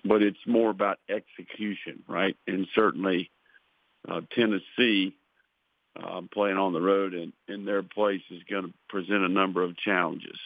Napier-SEC-Teleconference-09-21_1.wav